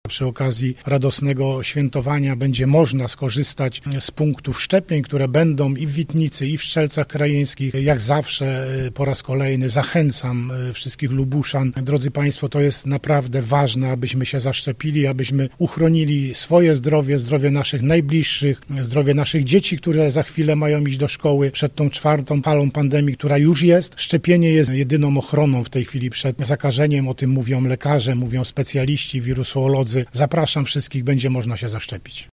Przy okazji zapraszamy wszystkich mieszkańców do szczepień – mówi wojewoda lubuski, Władysław Dajczak.
Wojewoda-o-mobilnych-szczepieniach.mp3